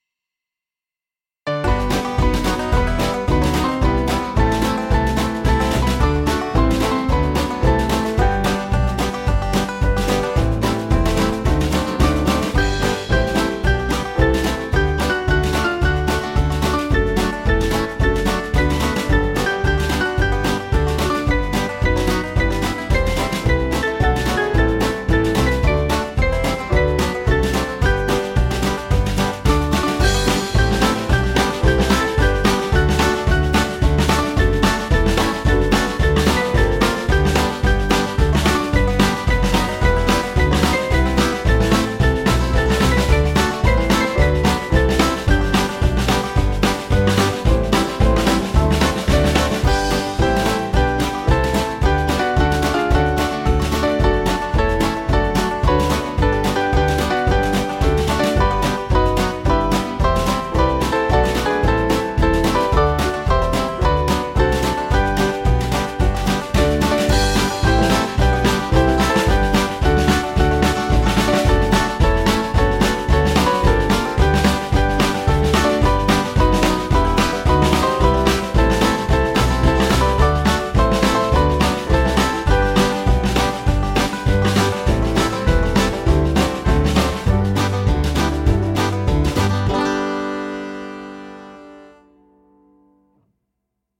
Kid`s club music
Small Band